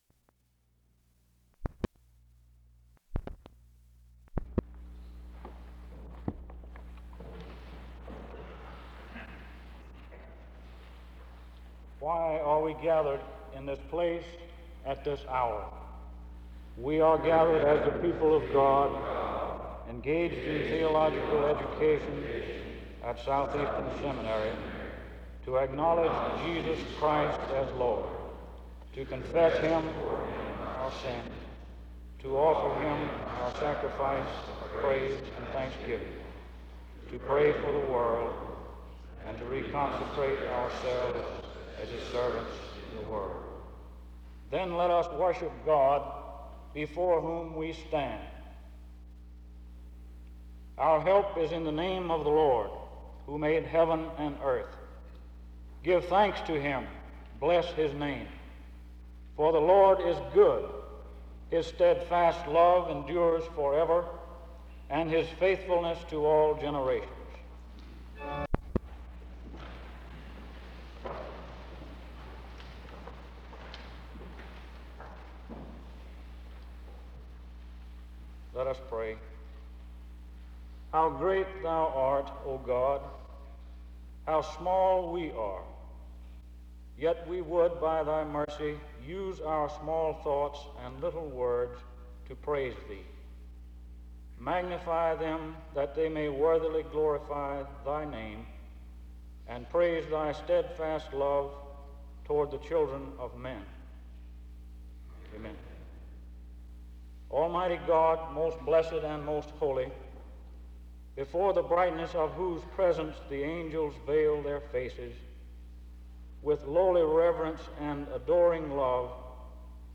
SEBTS Chapel
Download .mp3 Description The speaker and congregation gathered reads aloud the purpose statement of SEBTS (00:00-00:43), the speaker reads Psalm 124:8 and Psalm 100:4b-5 (00:44-01:17), and the speaker prays twice (01:18-02:19). Those gathered read a responsive reading together (02:20-03:25), and the speaker reads various texts of Scripture aloud (03:26-05:40).
A responsive reading is read (20:31-21:53), and a final prayer is given (21:54-22:46).